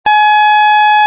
Ἡ διάρκεια τοῦ κάθε φθόγγου εἶναι 1 δευτερόλεπτο.
Κε = 440Hz
Κλίμακα Πα-Πα'
Οἱ ἤχοι ἔχουν παραχθεῖ μὲ ὑπολογιστὴ μὲ ὑπέρθεση ἀρμονικῶν.